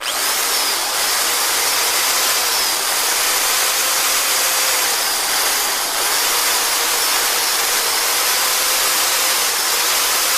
Play, download and share wiertarka original sound button!!!!
wiertarka.mp3